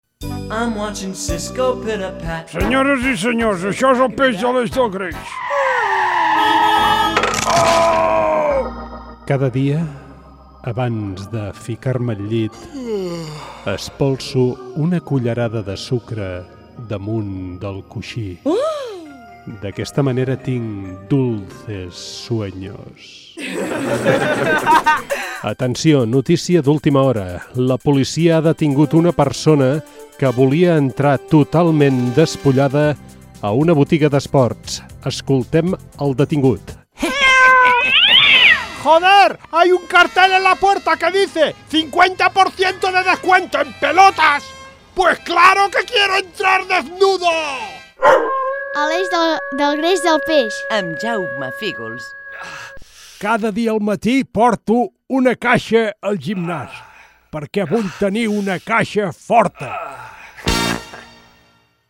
Miniespai humorístic: dolços somnis i la botiga d'esports amb identificació del programa
Entreteniment
Es tractava d'un minut d'humor amb frases absurdes, acudits, trucades de broma, paraules i expressions amb doble sentit... Tot ben guarnit amb molts efectes de so, ben picat i amb moltes veus diferents.